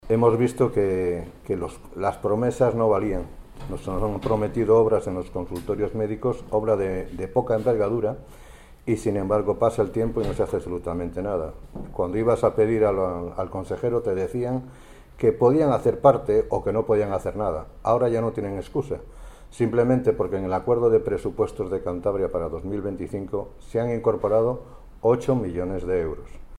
Ver declaraciones de Paula Fernández Viaña, diputada del Partido Regionalista de Cantabria y portavoz en materia de Sanidad; y de Eduardo Ortiz, vicepresidente de la Federación de Municipios de Cantabria.